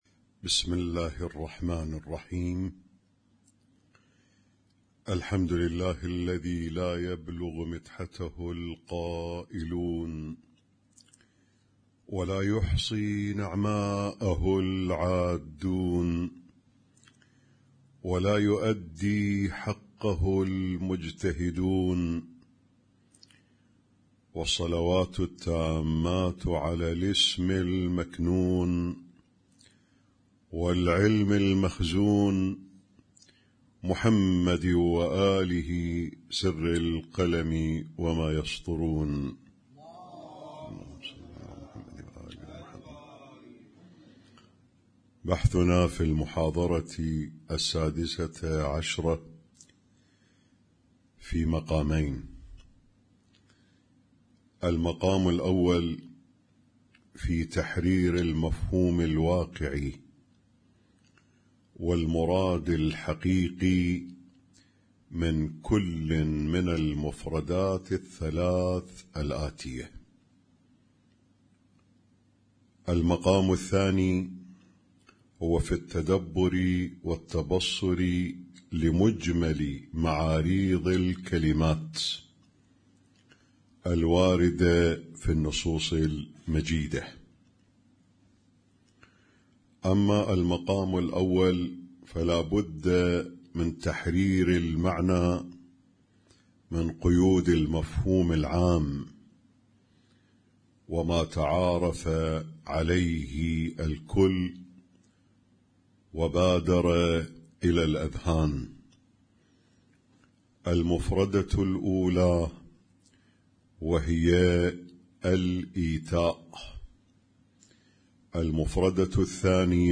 اسم التصنيف: المـكتبة الصــوتيه >> الدروس الصوتية >> الرؤية المعرفية الهادفة